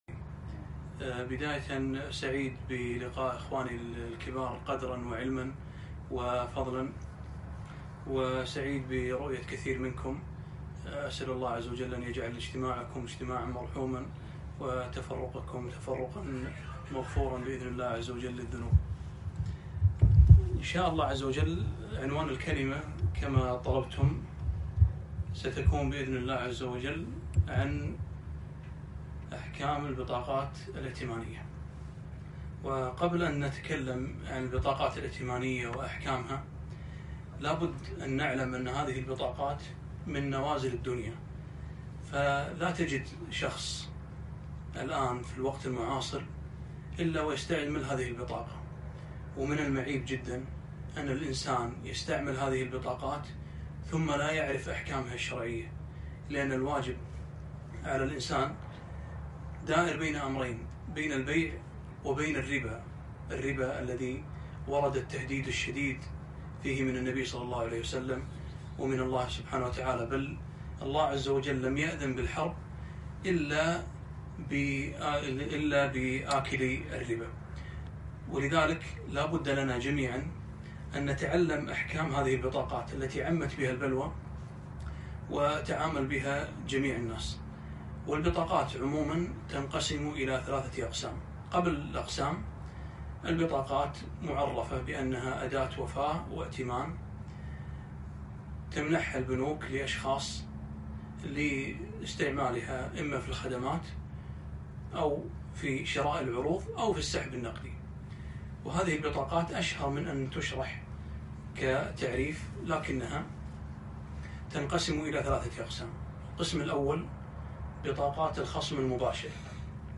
محاضرة - أحكام البطاقات الائتمانية - دروس الكويت